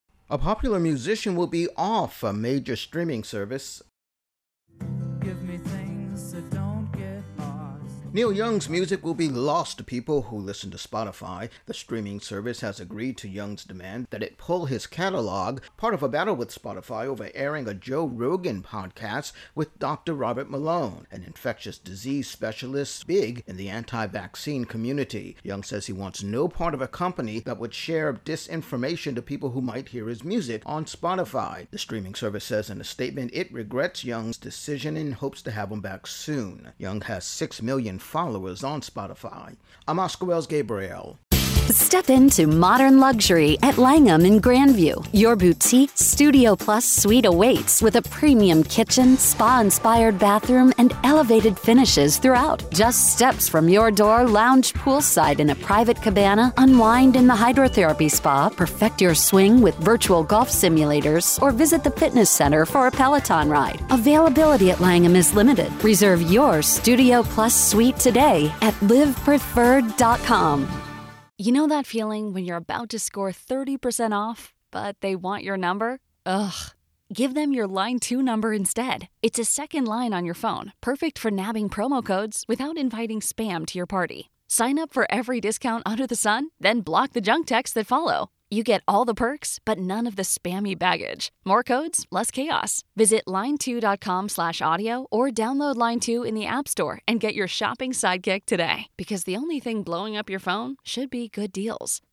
Intro+wrap on Neil Young's music being pulled from Spotify, at his request